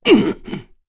Dakaris-Vox_Damage_kr_01.wav